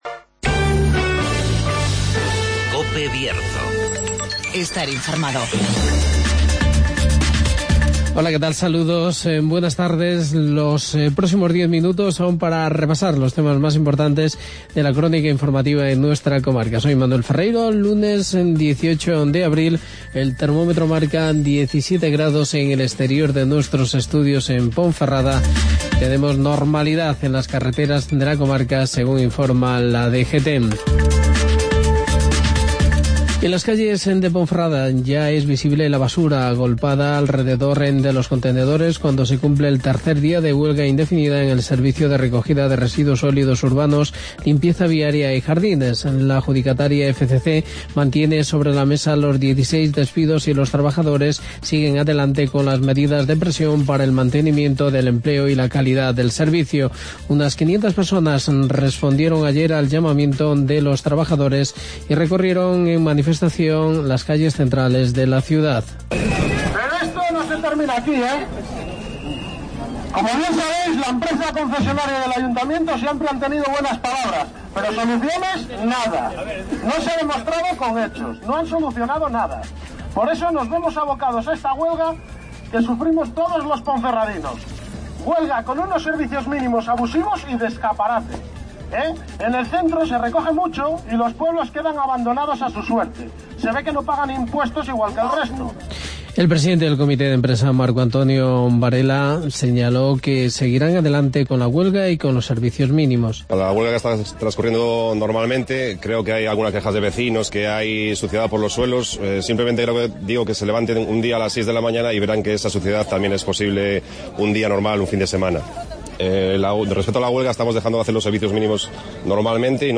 INFORMATIVO MEDIODÍA COPE BIERZO 180416